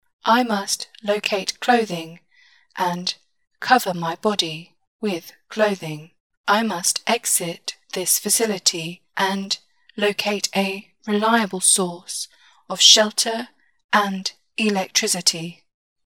Robot Voice Audio Tutorial
This next effect is created by lowering the pitch of the audio by 2%, then mixing it over the original audio.
MELI-example-Mix-Pitch.mp3